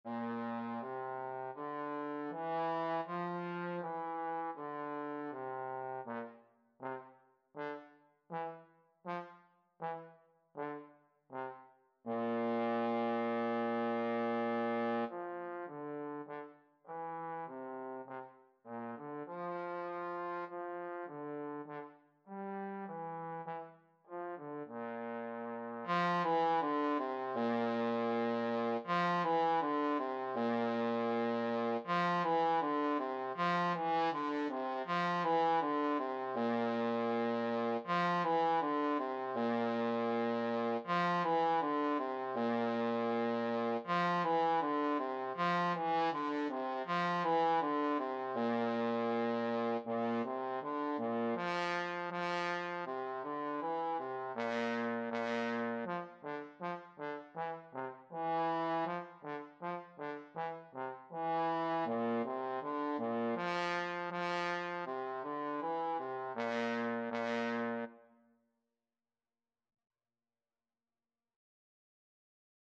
4/4 (View more 4/4 Music)
Beginners Level: Recommended for Beginners
Trombone  (View more Beginners Trombone Music)
Classical (View more Classical Trombone Music)